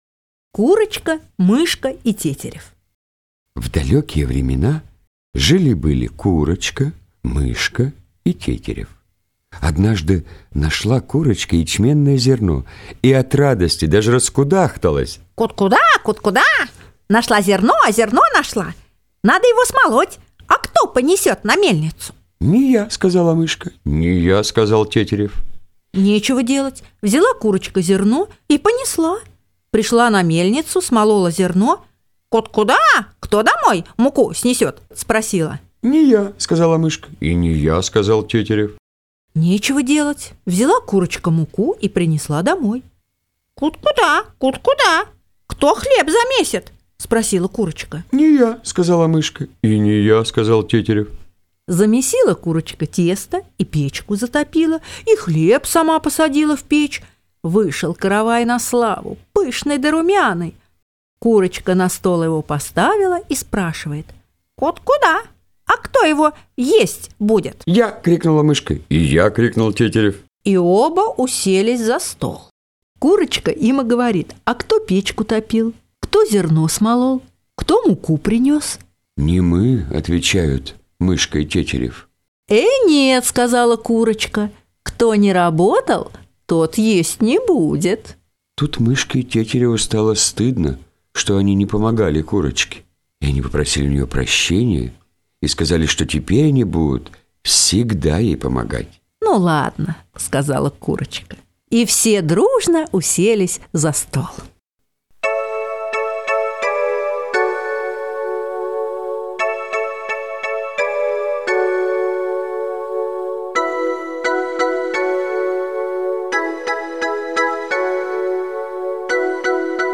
Курочка, мышка и тетерев - русская народная аудиосказка.